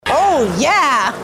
oh-yeah-female.mp3